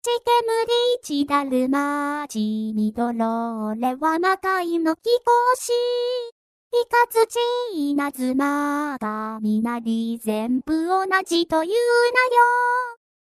クリスマスソングっぽいを曲をちょっと歌って頂いたところ、コレ違う言われ、駄目出しされましたよ。
まあ、確かにクリスマスというよりクラウザーさん向けの曲っぽいですが。